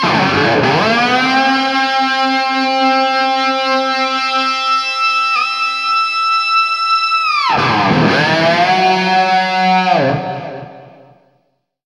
DIVEBOMB14-L.wav